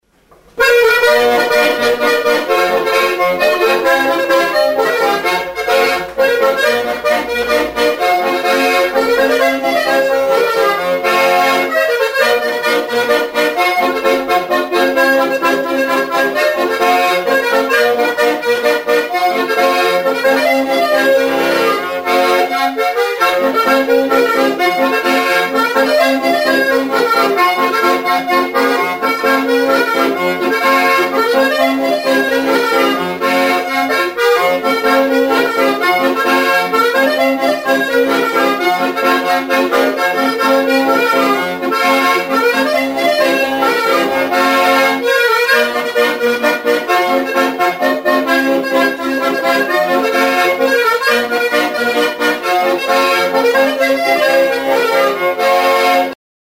Aerophones -> Reeds -> Single Free
Akordeoi diatonikoa.
/ DO# +50 tonuan.